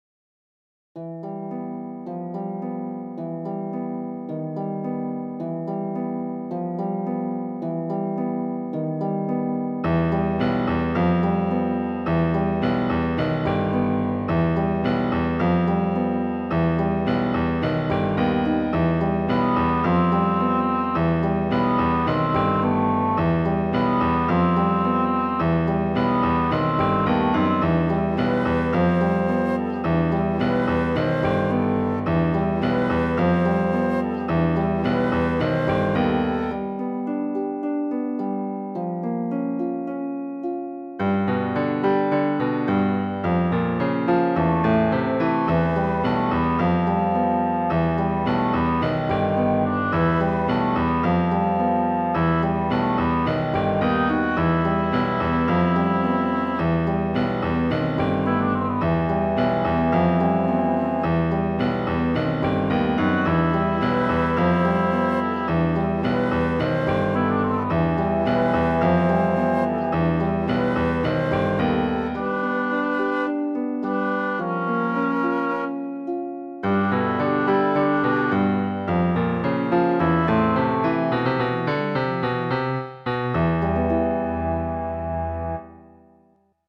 creepy
ILLUSTRATIVE MUSIC ; DIRGE MUSIC